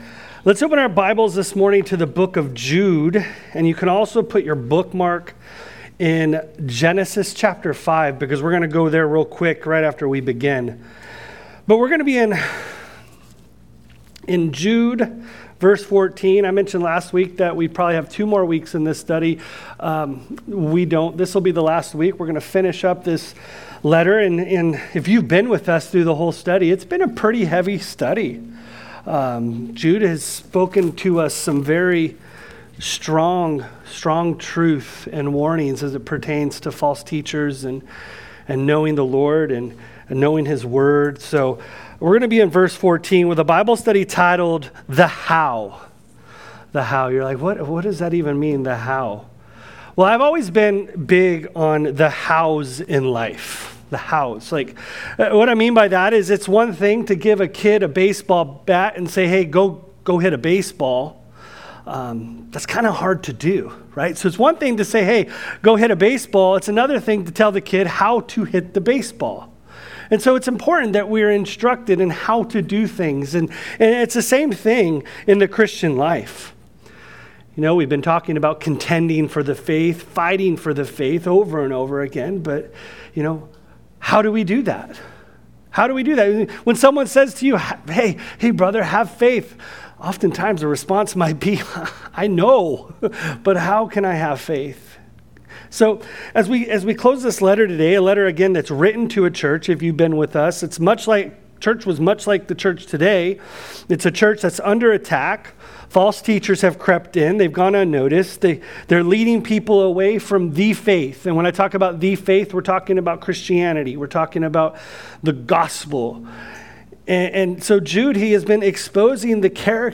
Sermon Series – Calvary Chapel West Ashley